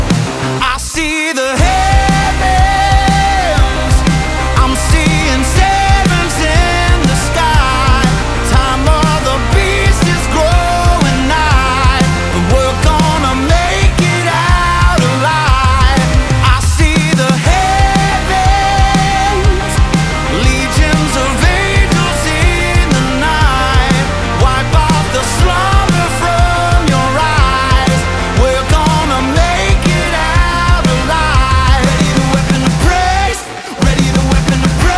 Christian Rock